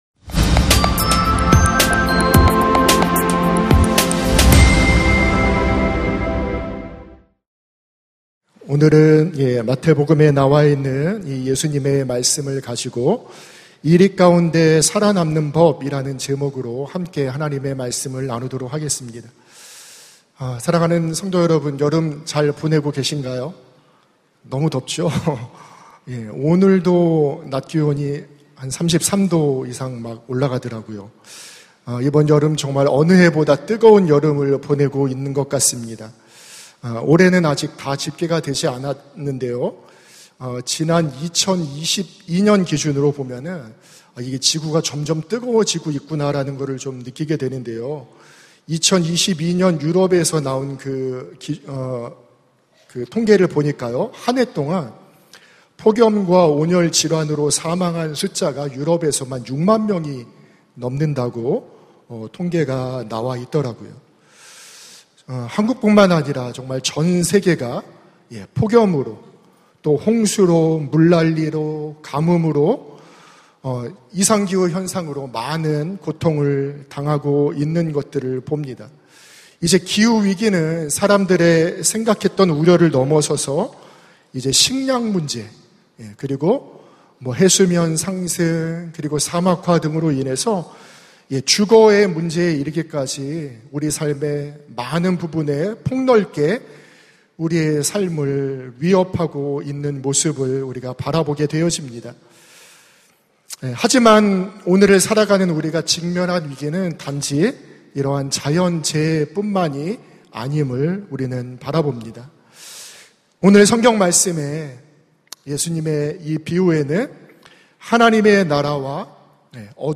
설교 : 금요심야기도회